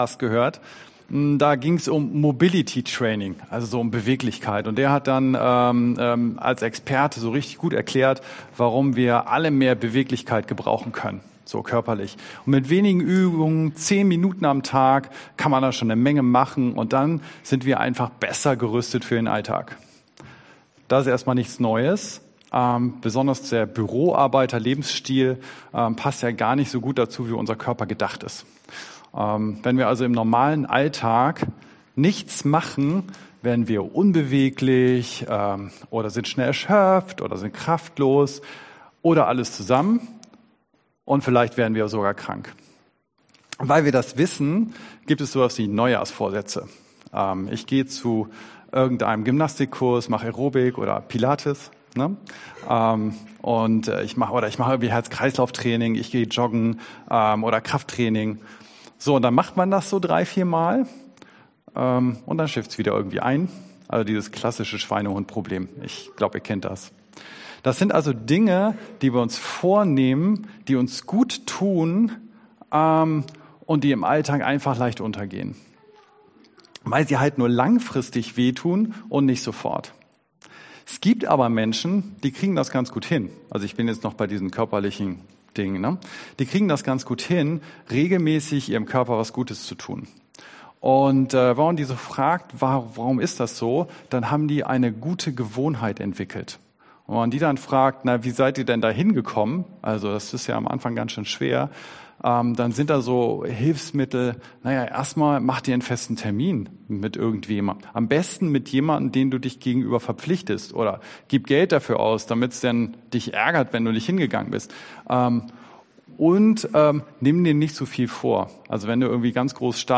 Tim 3,16; Mt 14,23; Hebr 10,24 Dienstart: Predigt « zur Ruhe kommen „Voller Erwartung“ oder „heute lieber nicht“ – wie gehst du in den Lobpreis?